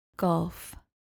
Pronounced: GOLF